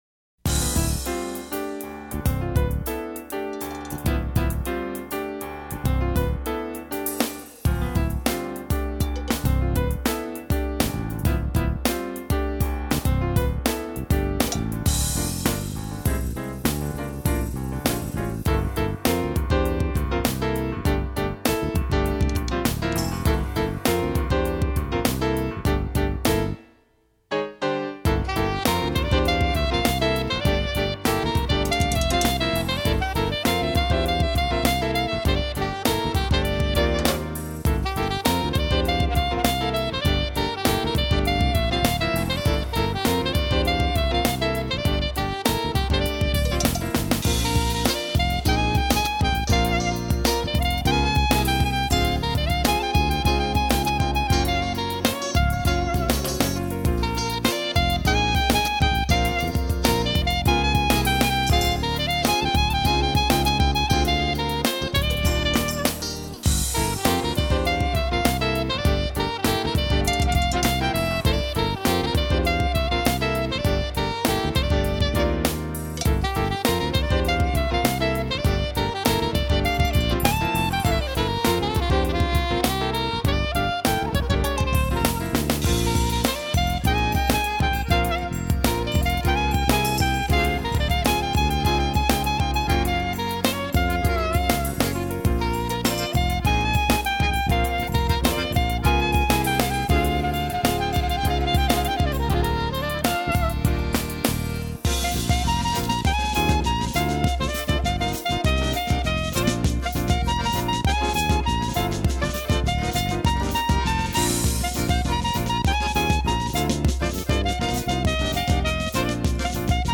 943   08:01:00   Faixa:     Jazz